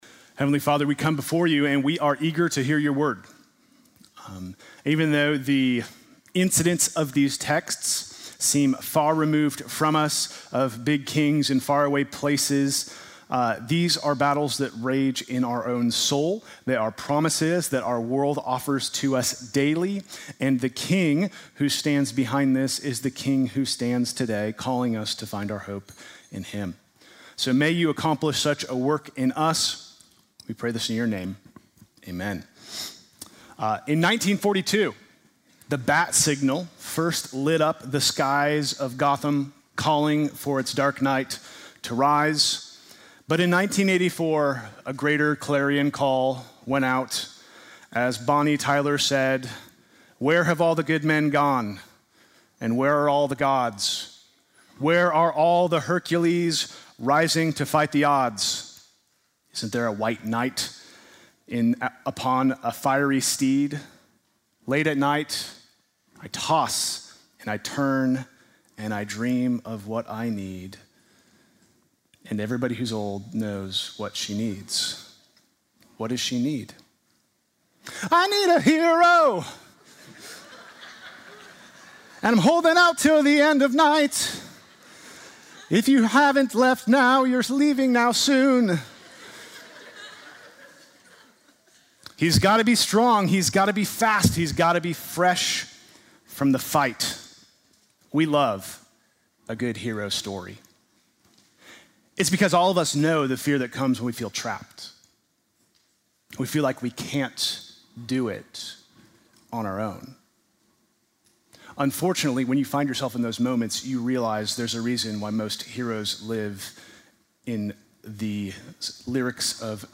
Sunday morning message February 8